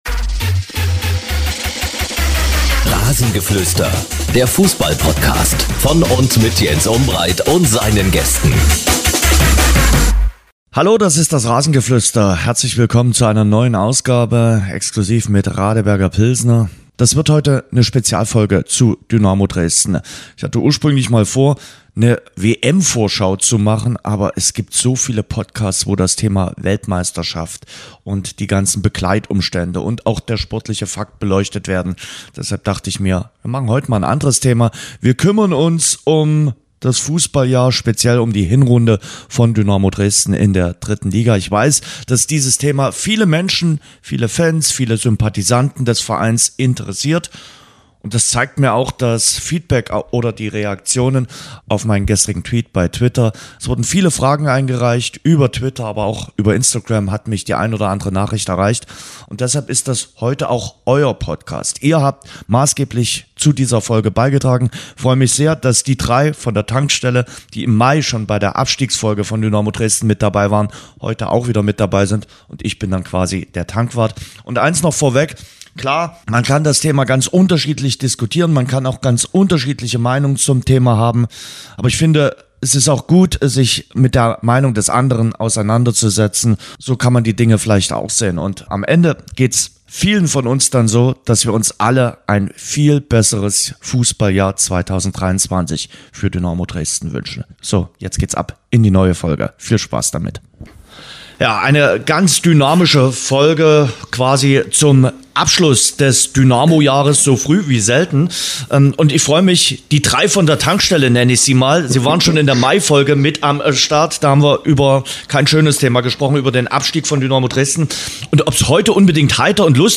Die drei Journalisten beantworten zahlreiche Hörerfragen rund um Dynamo Dresden.